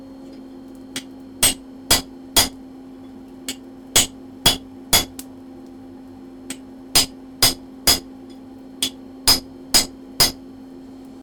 blacksmith-1.ogg